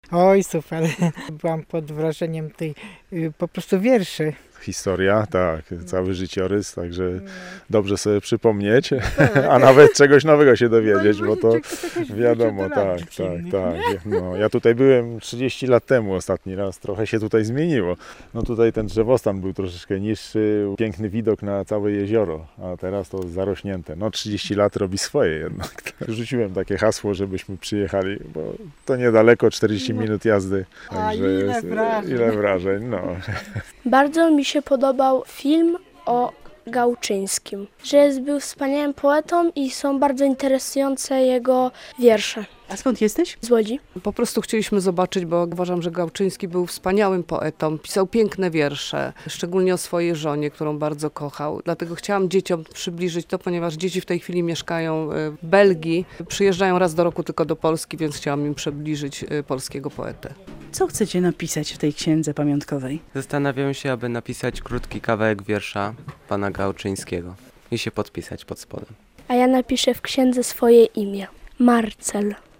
Zwiedzający opowiadają o swoich wrażeniach z odwiedzin muzeum